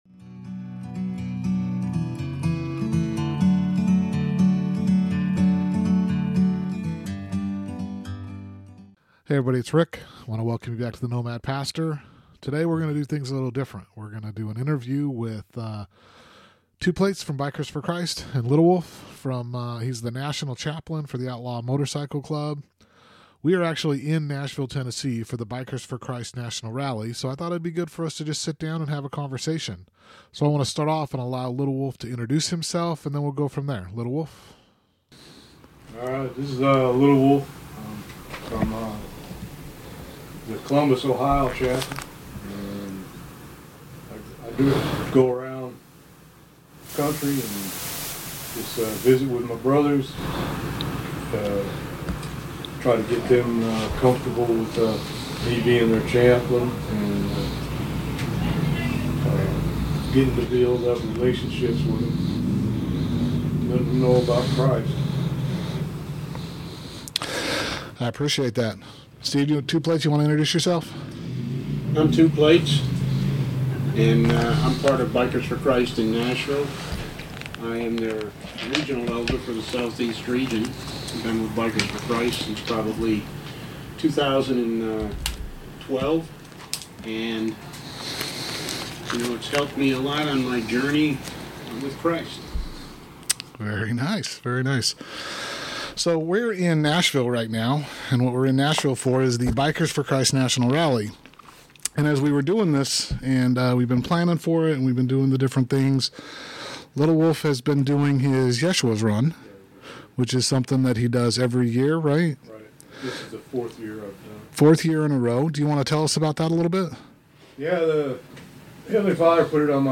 Lil Wolf Ministries Interview
BFC-National-Rally-Interview.mp3